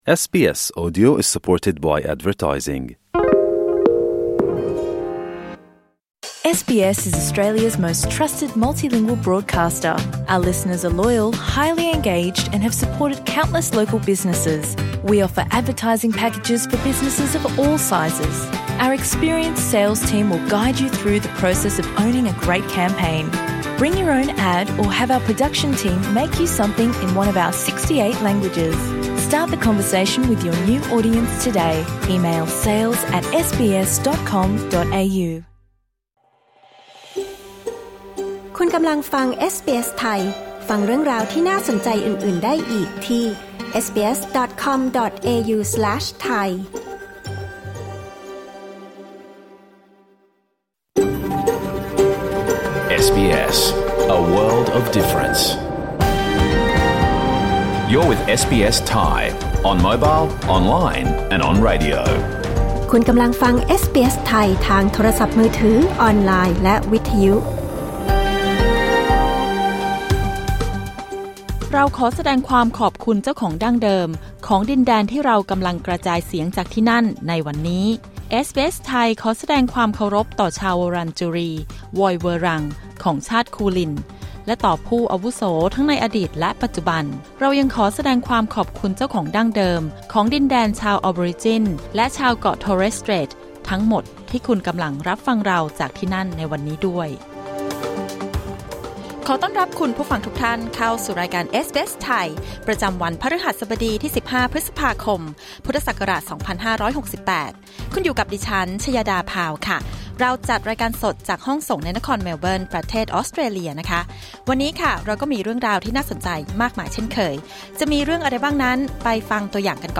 รายการสด 15 พฤษภาคม 2568